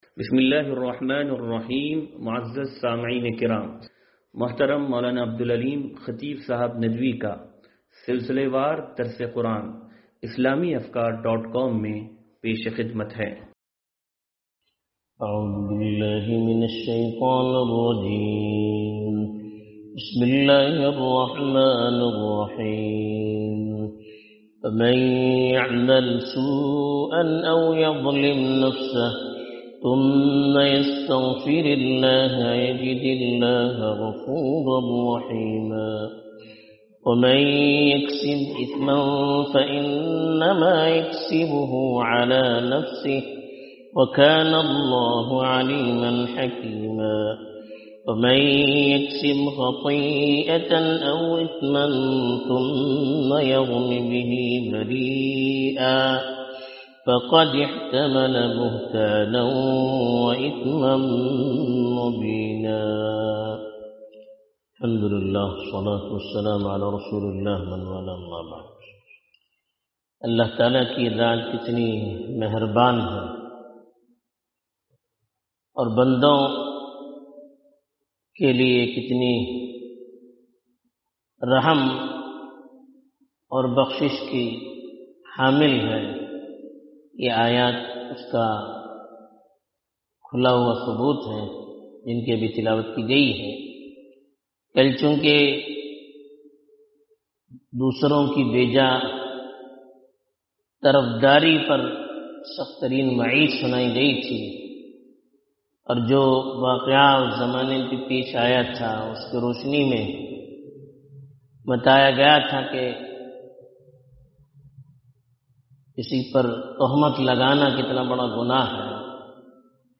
درس قرآن نمبر 0390